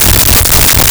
The Main Reactor Loop 02
The Main Reactor Loop 02.wav